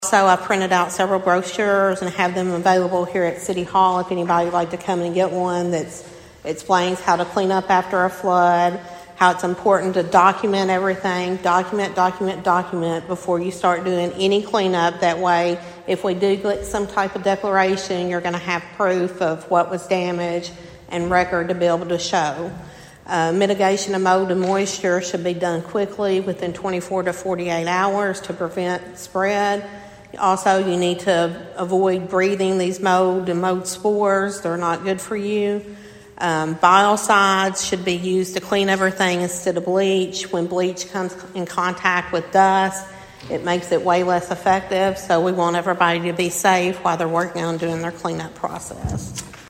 At the City Council meeting on Monday night